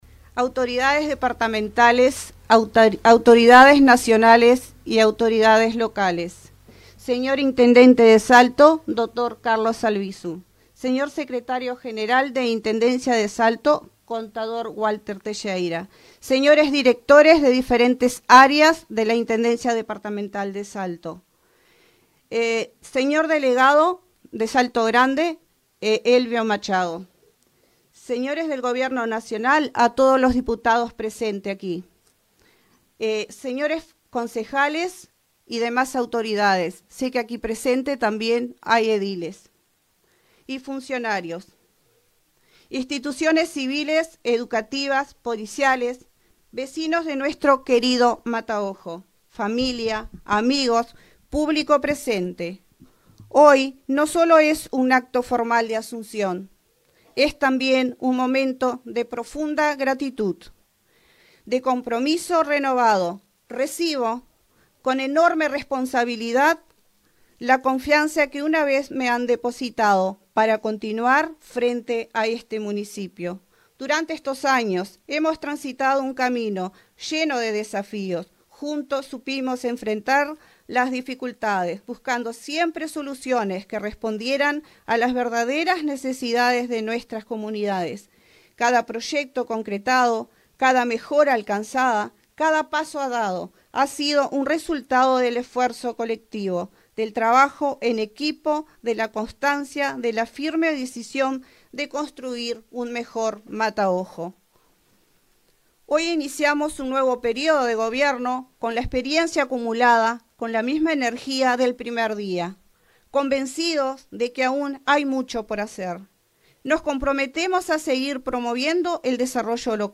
En su discurso de asunción, Rosita Moreno reafirmó su compromiso con el desarrollo integral de Mataojo, un municipio caracterizado por su diversidad y lejanía geográfica.